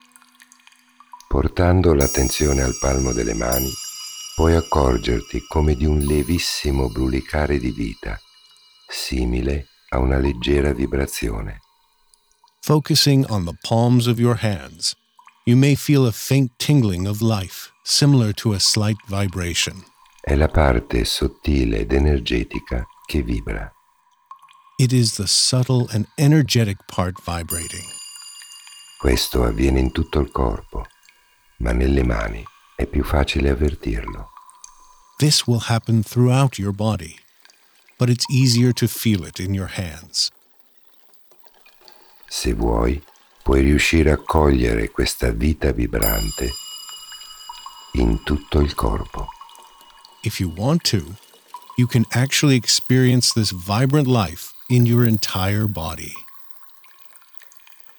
Initiation-into-trascendence-Two-voices-1.m4a